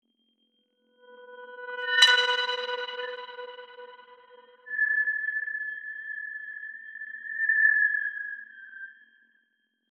Light towards the top of the image is mapped to higher-pitch sound, with radio, infrared, and X-ray light mapped to low, medium, and high pitch ranges.
sonify10_ss433_xray.wav